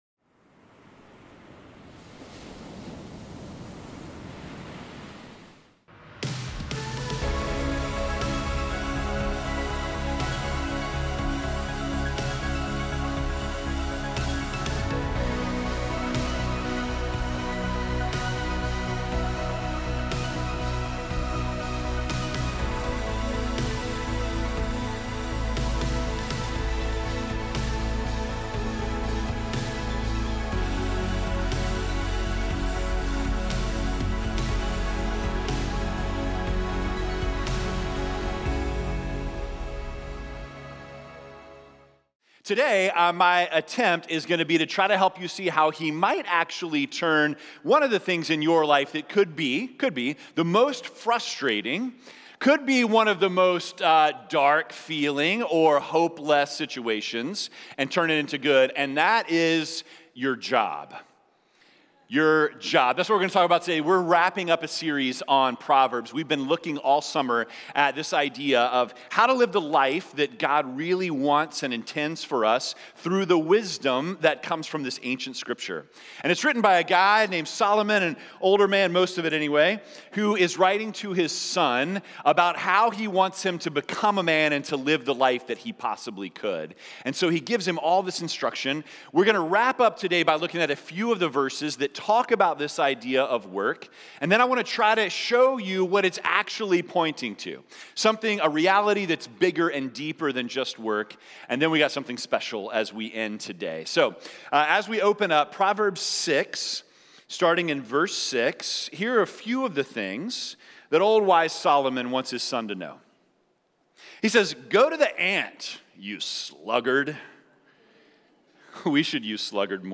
Together we will explore topics such as our words, humility, integrity, and fear of the Lord. Sermon Series: July 16, 2023 – August 27, 2023